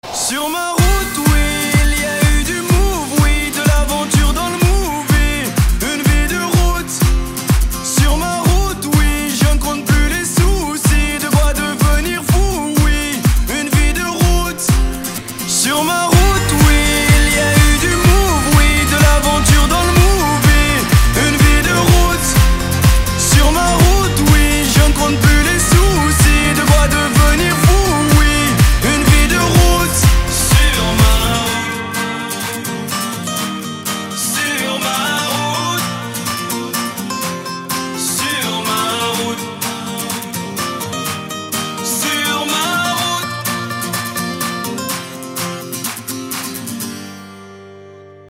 • Качество: 320, Stereo
гитара
мужской вокал
рэп
dance
французский рэп